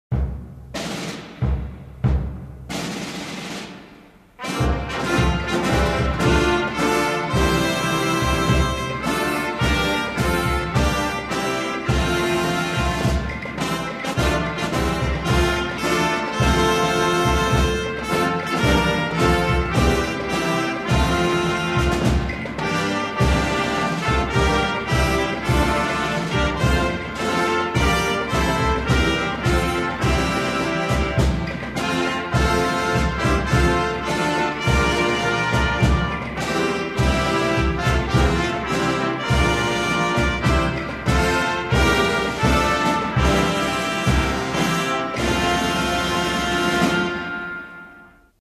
National_anthem_of_Nigeria.mp3